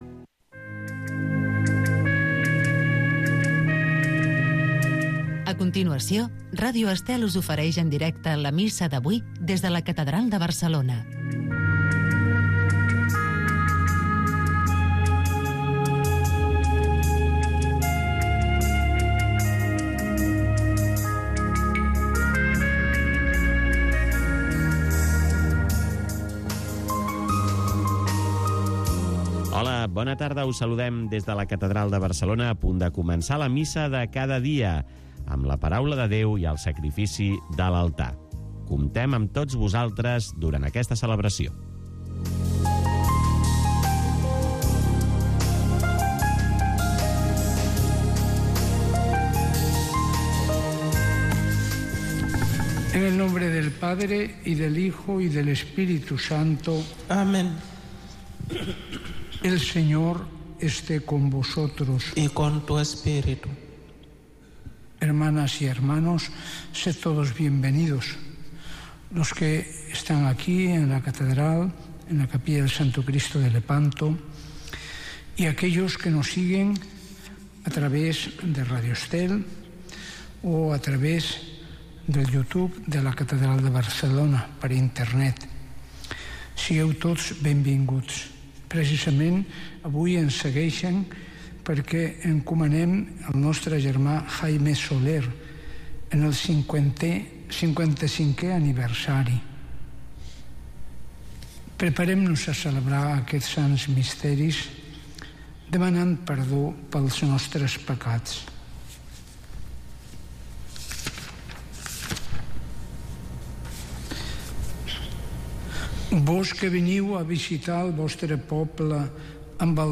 La Missa de cada dia. Cada dia pots seguir la Missa en directe amb Ràdio Estel.